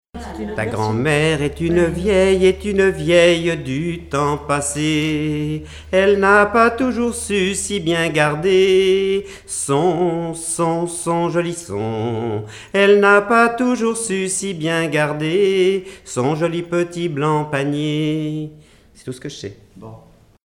Notre-Dame-de-Monts
Genre laisse
Chansons traditionnelles
Pièce musicale inédite